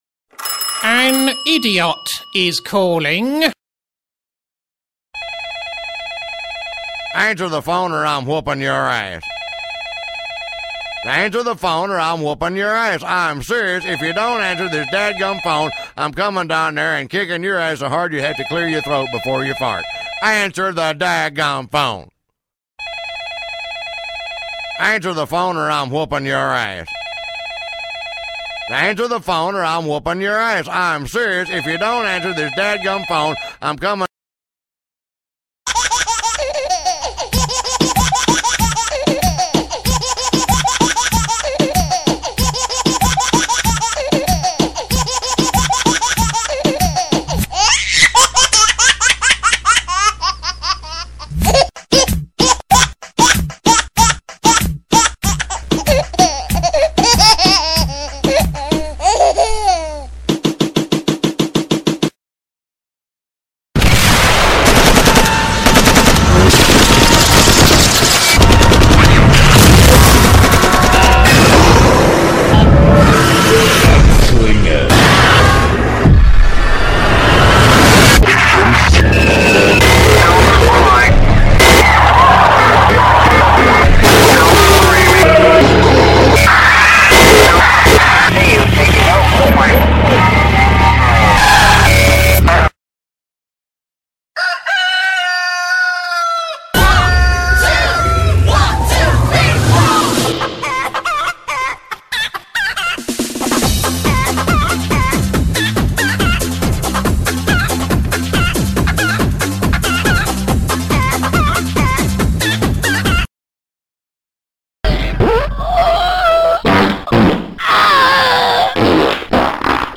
Helinaid saab kuulata allolevast pleierist, kus iga helina vahele jätsin 1-2 sekundilise vahe, et oleks parem mõista, kus eelnev helin lõppes ja sellele järgnev algab.
Samuti sisaldavad mõningad helinad ebaeetilist (loe: roppu) teksti!
Mobiilihelinad.mp3